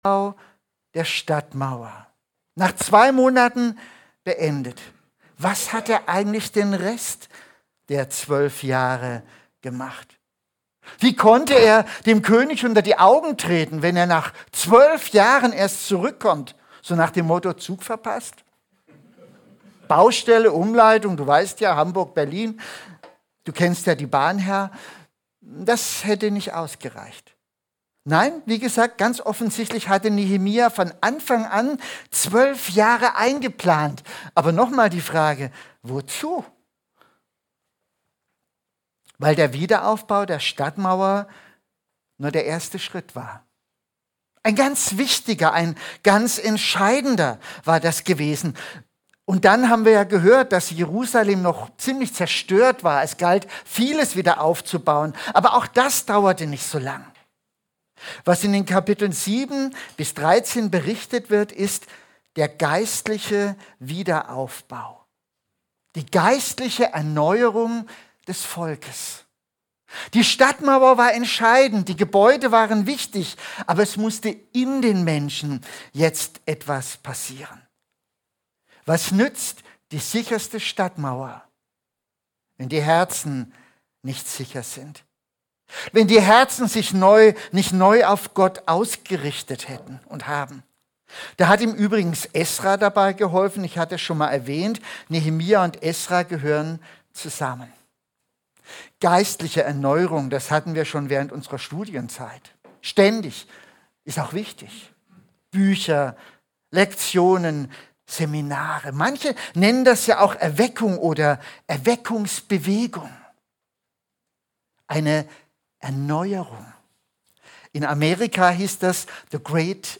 EFG Mühltal – Predigten